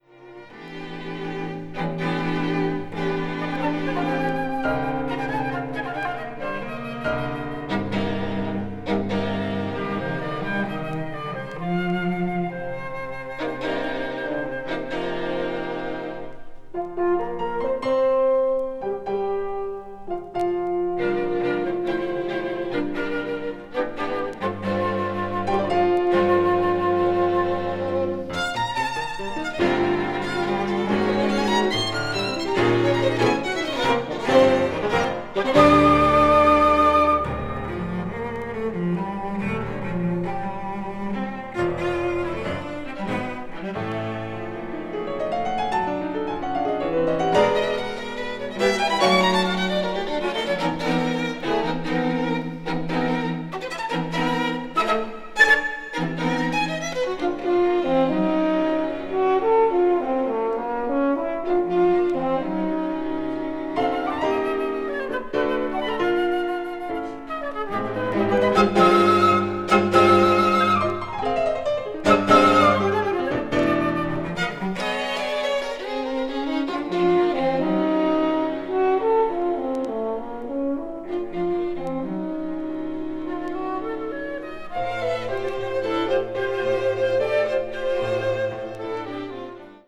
media : EX-/EX-(わずかなチリノイズ/軽いチリノイズが入る箇所あり)